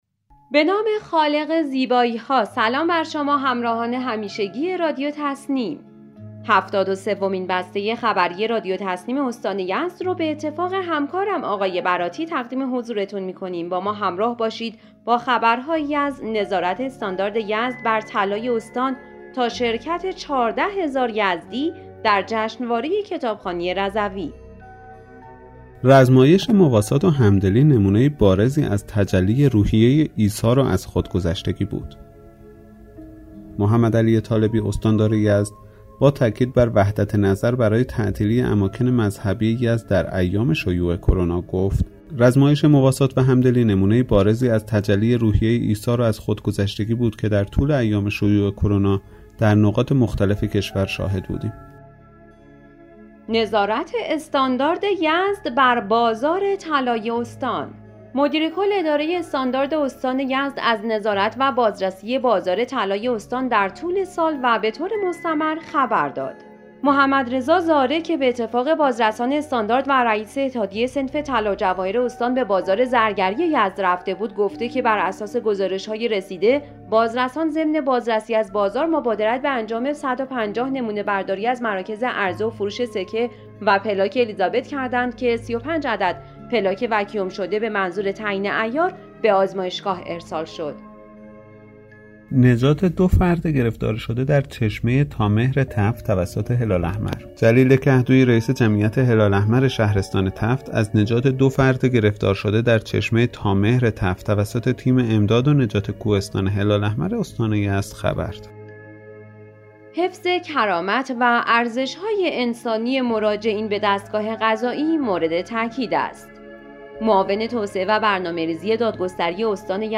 به گزارش خبرگزاری تسنیم از یزد, هفتاد و سومین بسته خبری رادیو تسنیم استان یزد با خبرهایی از نظارت استاندارد یزد بر بازار طلای استان, تقدیر استاندار یزد از جشنواره‌های مواسات و همدلی، نجات جان دو فرد گرفتار شده در چشمه تامهر تفت، اعلام برندگان مسابقه کتابخوانی جرعه مهدوی، تقدیر مدیرکل بنیاد شهید یزد از خدمات ناجا در حفظ امنیت کشور و یزد، توصیه معاون توسعه و برنامه‌ریزی دادگستری استان بر حفظ کرامت مراجعین به دستگاه قضا, بازدید مدیرعامل برق منطقه‌ای از پروژه توسعه پست خضرآباد و حضور بیش از 14 هزار یزدی در جشنواره کتابخوانی رضوی منتشر شد.